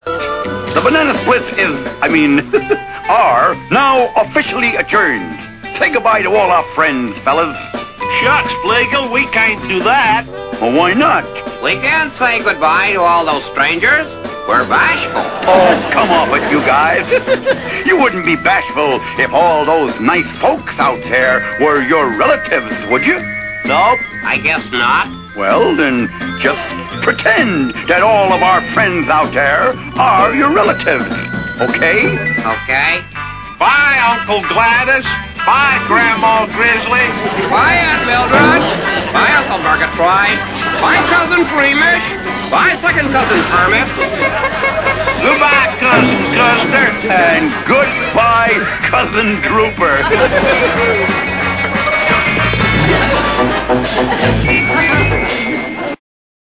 The Banana Splits say goodbye.